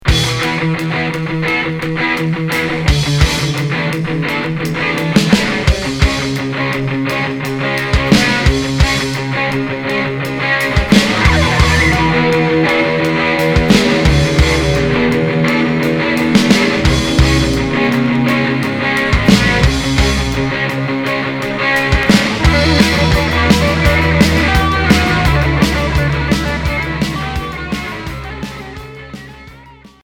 Hard